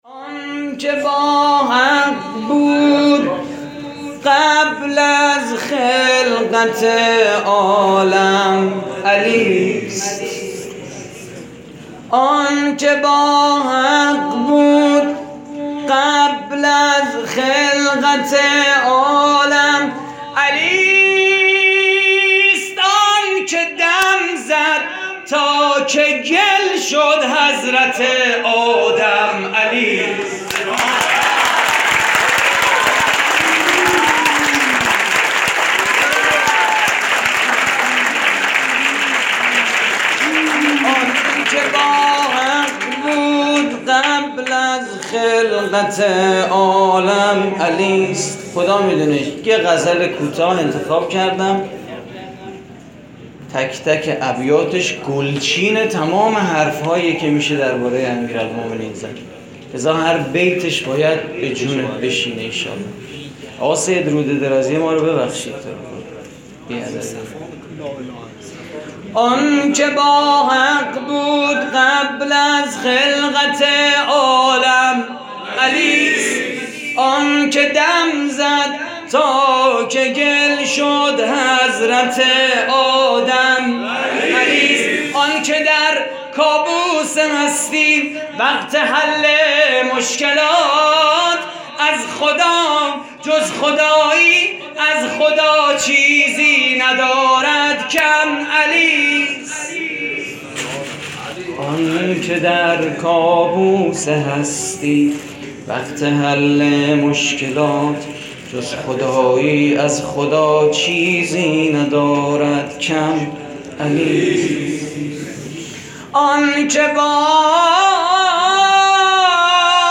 مراسم جشن عید غدیر / هیئت کریم آل طاها (ع) - نازی آباد؛ 16 شهریور 96
صوت مراسم:
مدح: آنکه با حق بود قبل از خلقت عالم علی‌ست؛ پخش آنلاین |